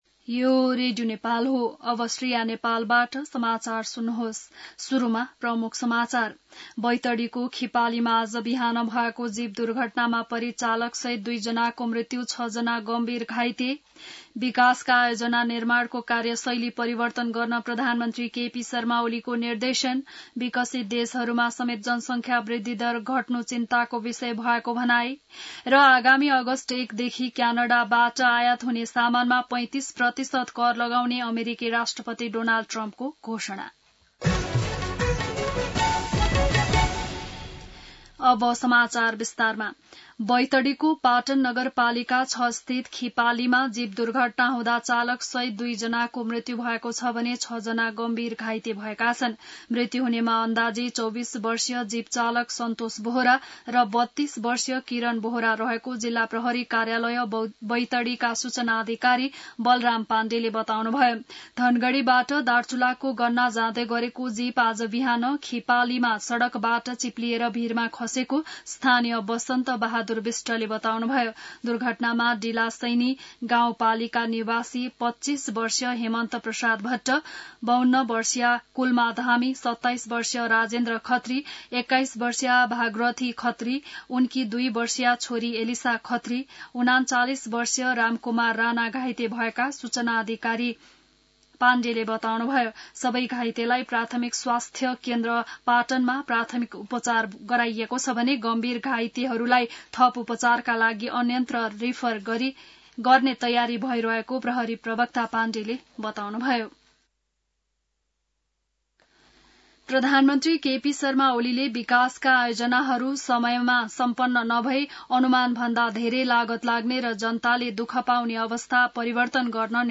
An online outlet of Nepal's national radio broadcaster
बिहान ९ बजेको नेपाली समाचार : २८ असार , २०८२